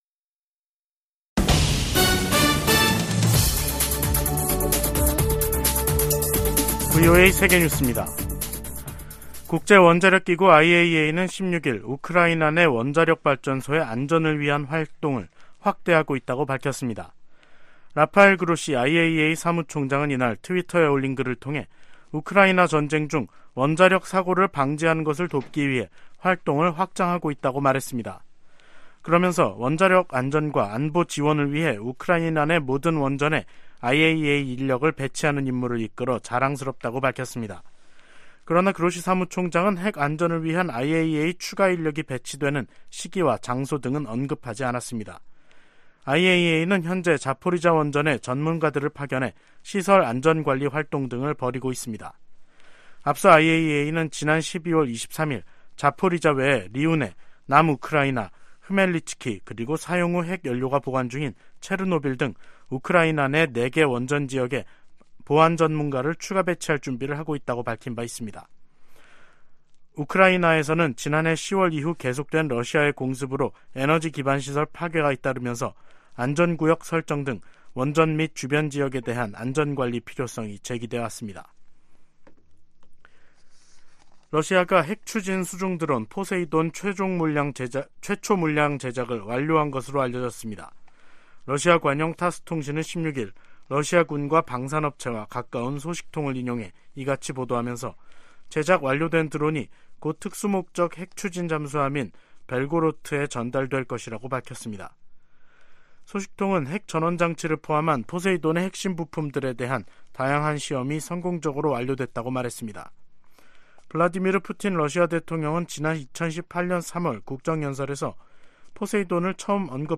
VOA 한국어 간판 뉴스 프로그램 '뉴스 투데이', 2023년 1월 16일 3부 방송입니다. 조 바이든 미국 대통령과 기시다 후미오 일본 총리가 워싱턴에서 열린 정상회담에서 한반도 비핵화와 북한의 납치 문제 등을 논의했습니다. 지난해 미국과 한국 정부의 대북 공조가 더욱 강화됐다고 미국 의회조사국이 평가했습니다.